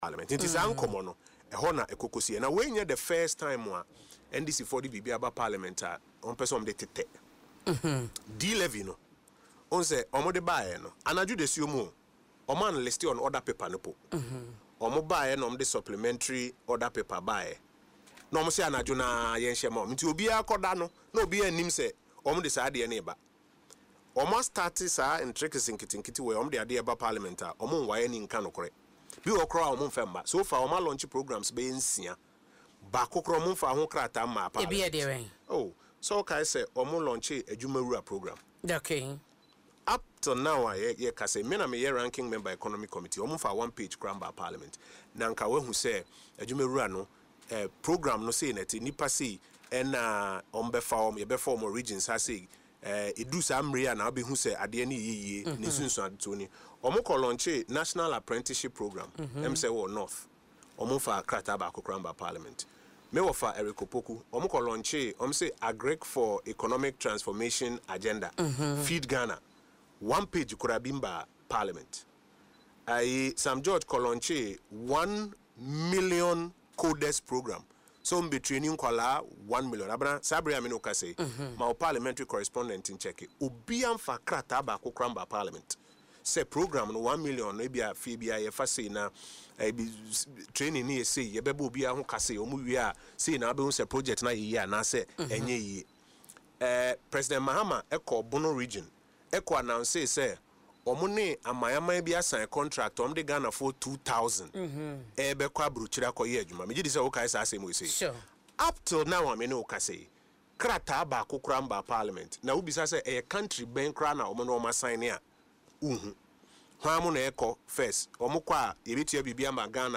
Speaking in an interview on Adom FM’s Dwaso Nsem morning show, the former Information Minister expressed worry over what he described as a growing trend of the government launching major programmes without presenting the necessary documentation to Parliament.